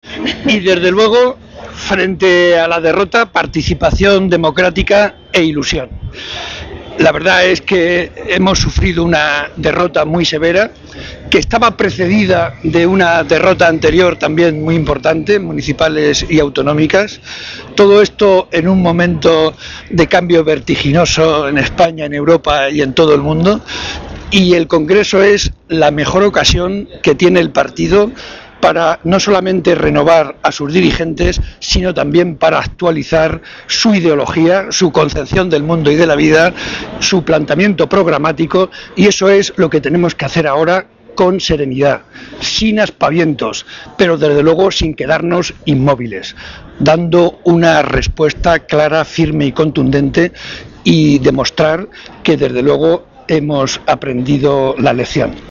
Barreda, que realizó estas manifestaciones a la entrada al Comité Federal que los socialistas están celebrando hoy en Madrid, argumentó que hemos sufrido una derrota muy severa que, además, está precedida de una derrota también muy importante en las elecciones municipales y autonómicas.
Cortes de audio de la rueda de prensa